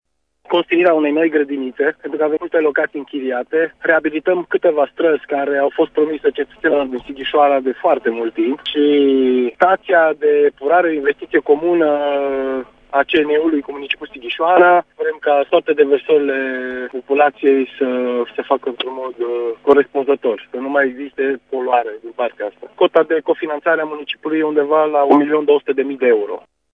Viceprimarul Sighişoarei, Dan Bândea, a menţionat printre proiecte construirea unei grădiniţe, reabilitarea unor străzi şi o staţie de epurare, finantata din fonduri europene.